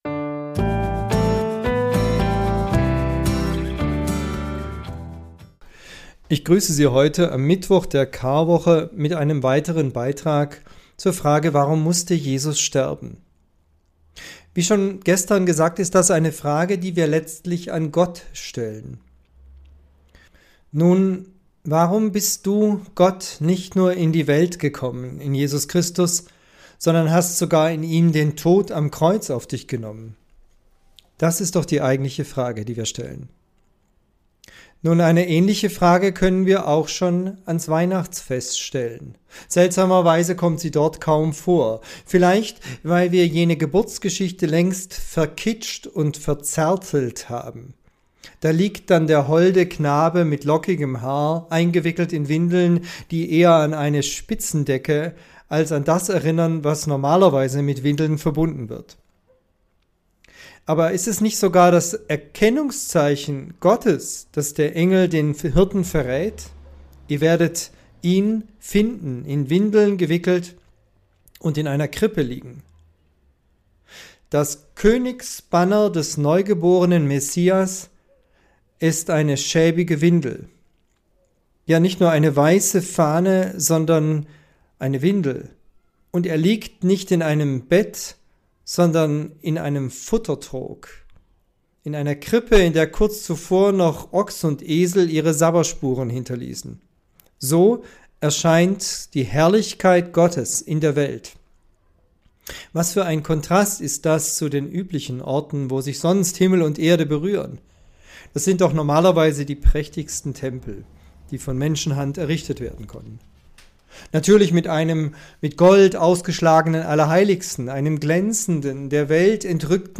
Tübinger Telefonandacht zur Tageslosung Podcast